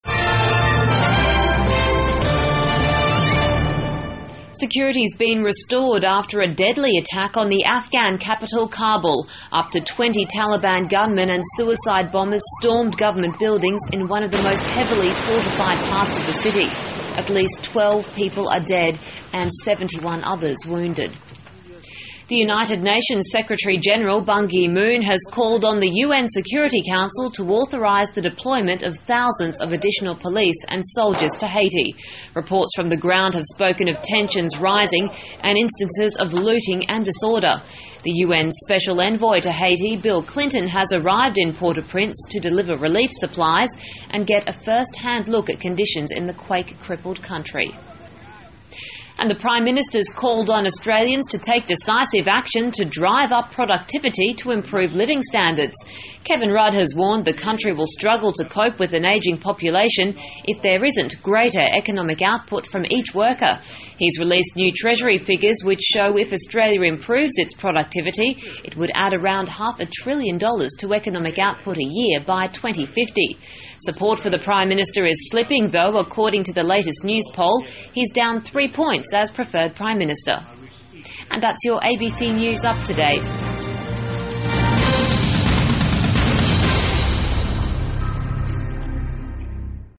澳洲新闻 (ABC新闻快递) 2010-01-19 听力文件下载—在线英语听力室